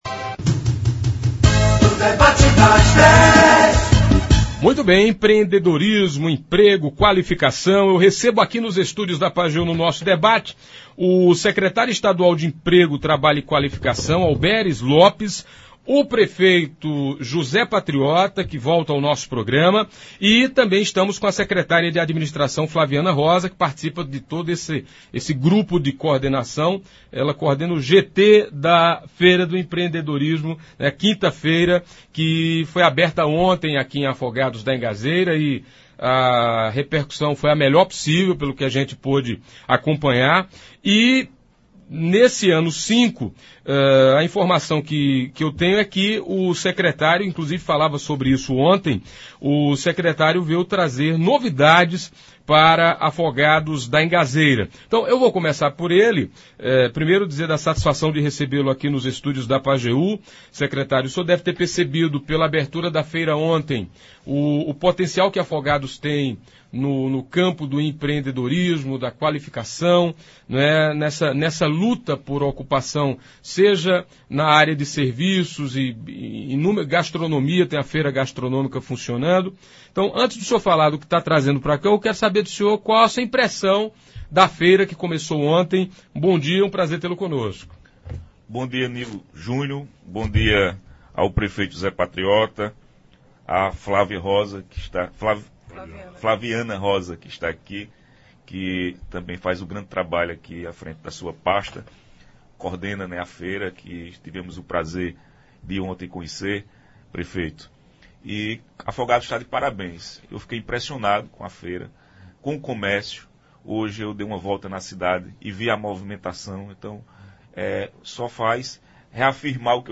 Falaram também sobre a veia empreendedora de Afogados da Ingazeira e lógico sobre a Feira que acontece até o próximo sábado (28). Ouça abaixo a íntegra do debate de hoje: